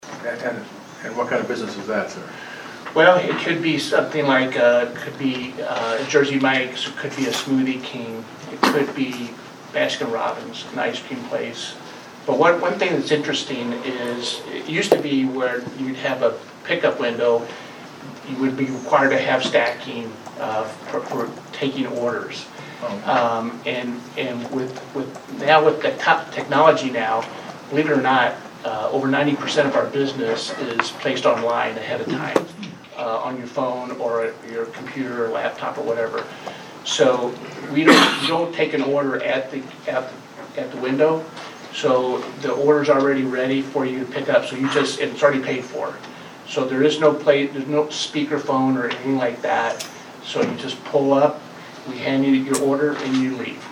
At Monday’s Vandalia City Council meeting, representatives from Domino’s Pizza were on hand for the meeting as they were requesting TIF funding.  The new Domino’s would be located between Los Amigo’s and O’Reilly’s on Veteran’s Avenue in Vandalia.